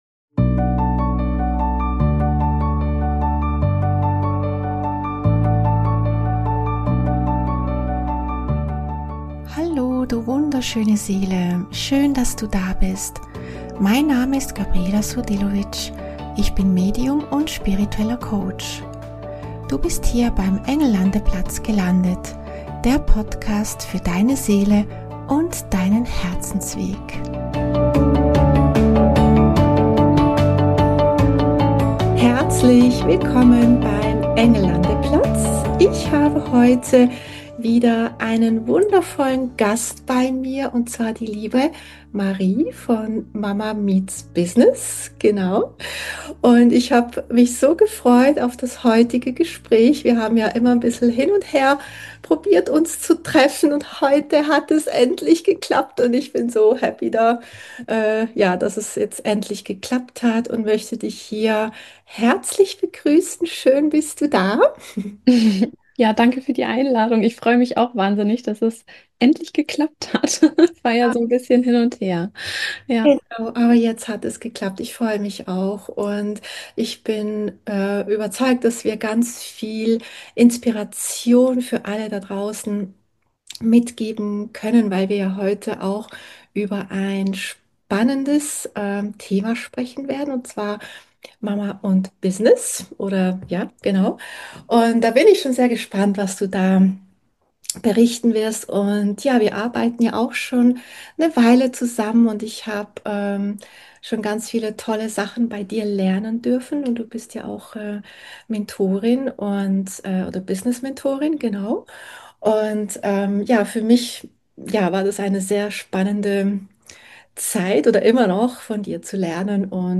In dieser Folge habe ich wieder einen Gast zu Besuch beim Engel Landeplatz.